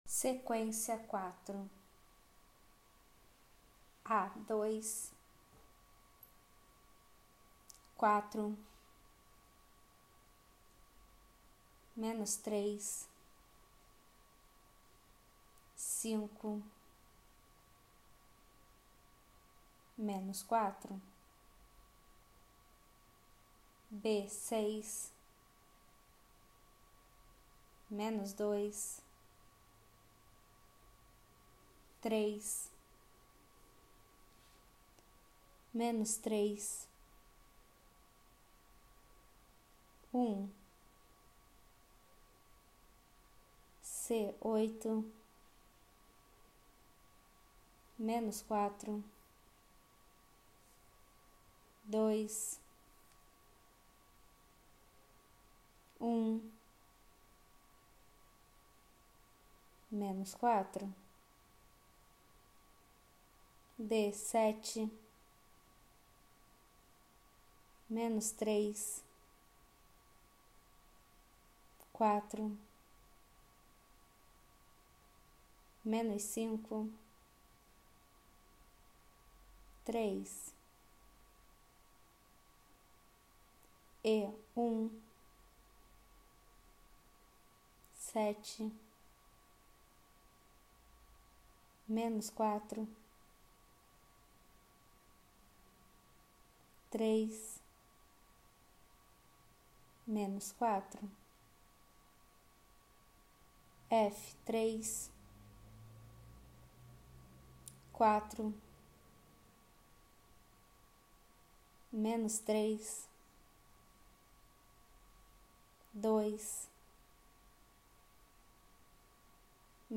Ditado
Ditados com troca de base 5 - Lento